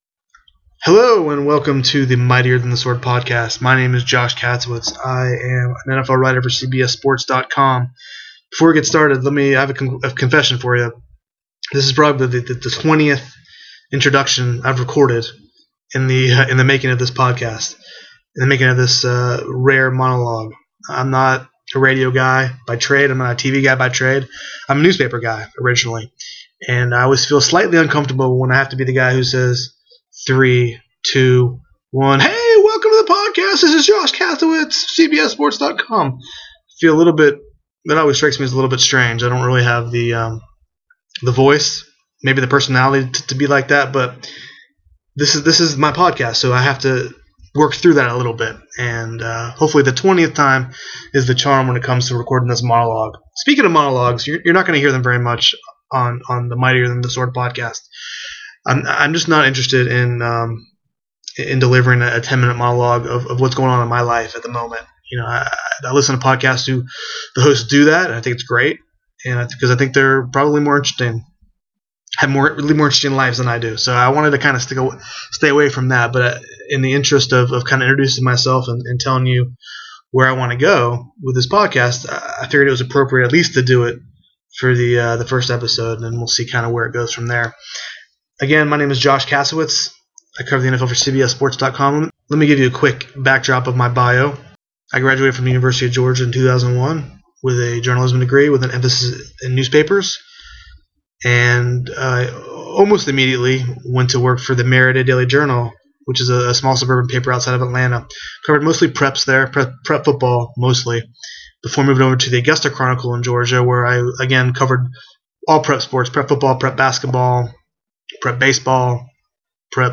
That’s why I’ll mostly refrain from monologues before I introduce my weekly guest.